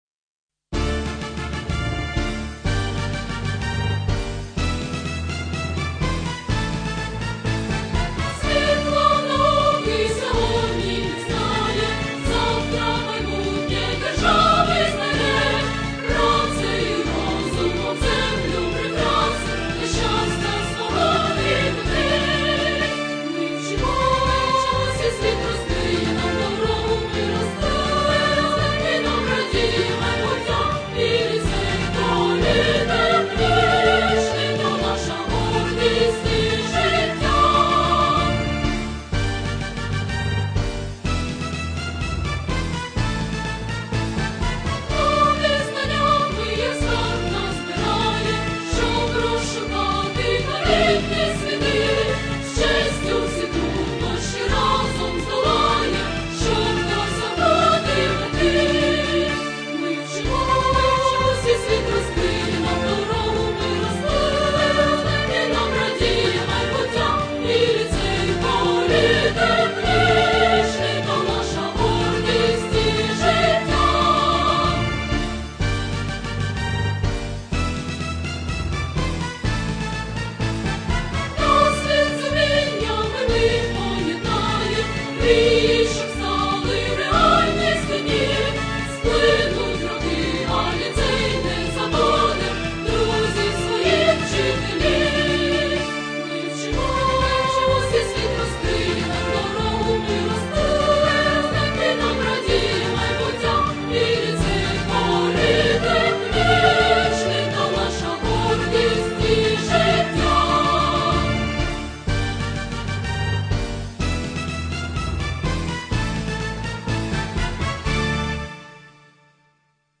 Марш